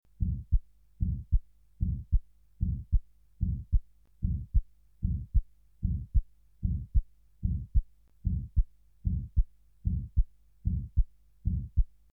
03_Aortenstenose.mp3